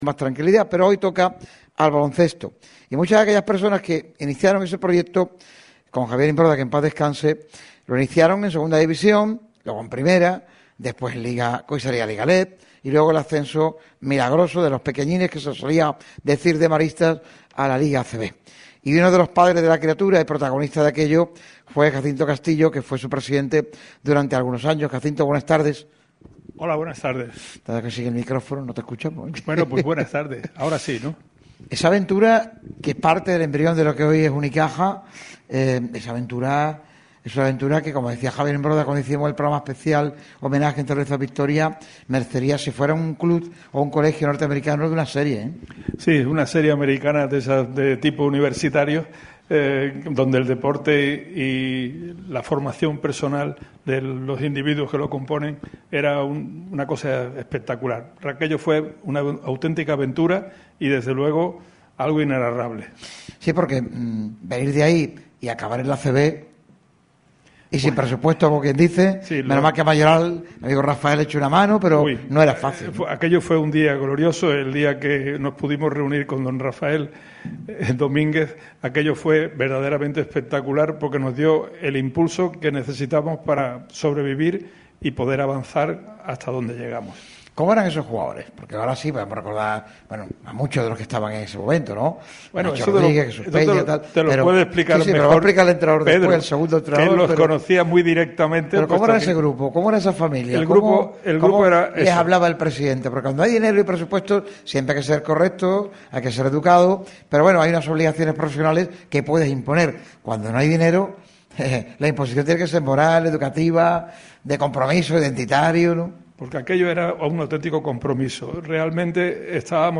diversos integrantes de una amplia mesa de charla en la radio del deporte. En sus intervenciones ha repasado el contexto en la Costa del Sol del deporte de la canasta y la ‘naranja’, recordando el principio de lo que hoy se materializa como emblema de Málaga.